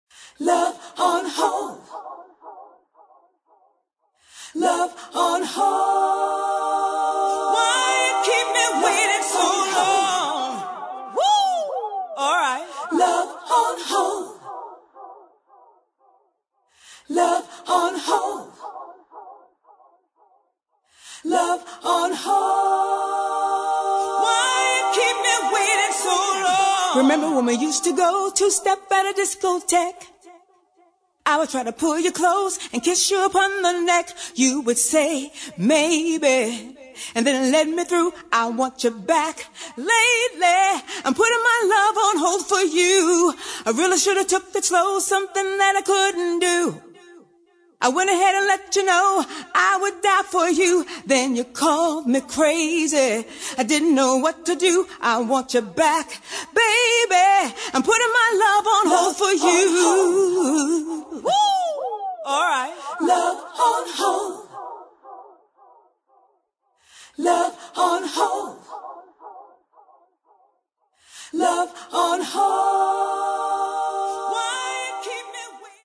[ DISCO ]
Accapella